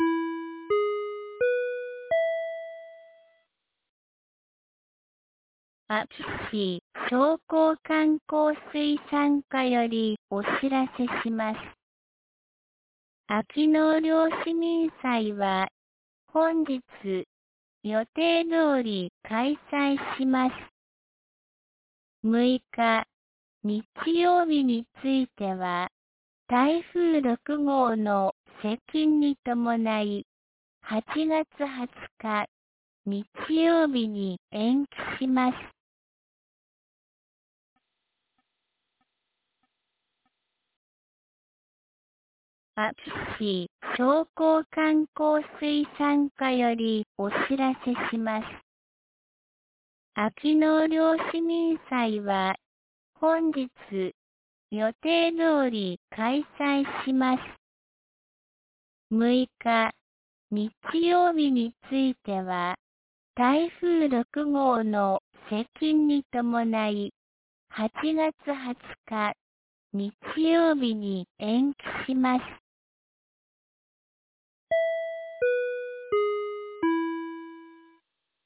2023年08月05日 12時31分に、安芸市より全地区へ放送がありました。